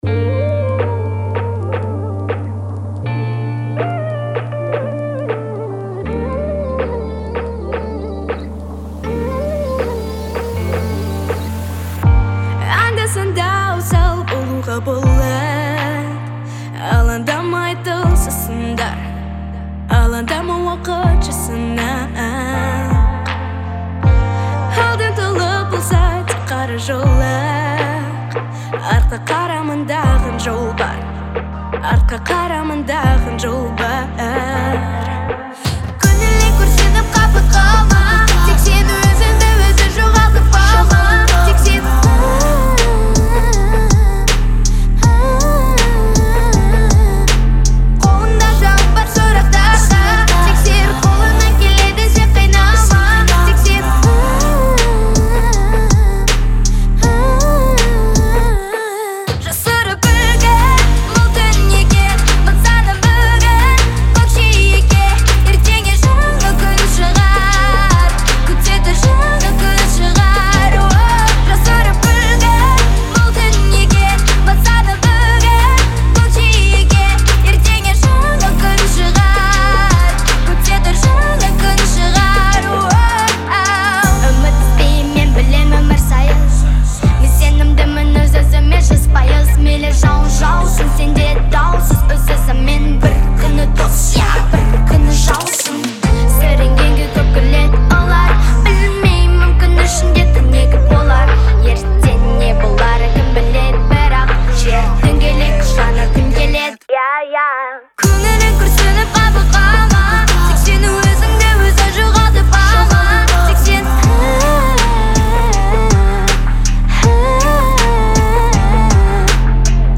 современными битами и запоминающимися мелодиями
отличается выразительностью и эмоциональностью